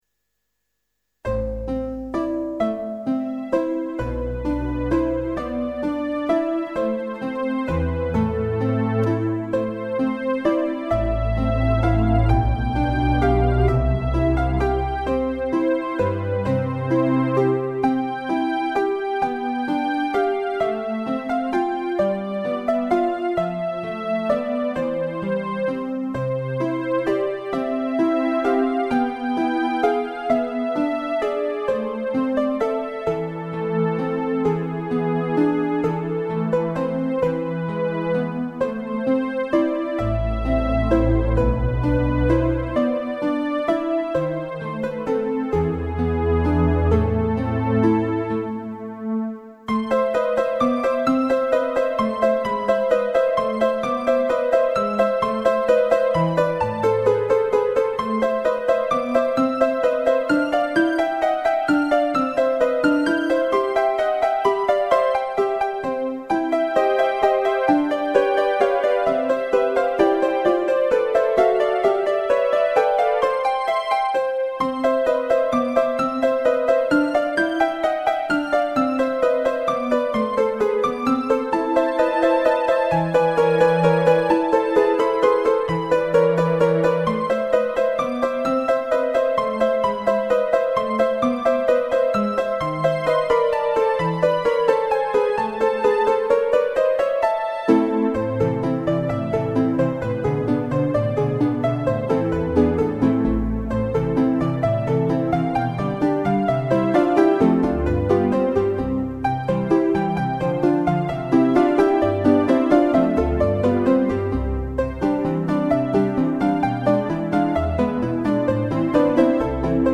Thema en variaties in a Zoals de titel zegt: een thema; met variaties; (in a),
Tema en Var in a.mp3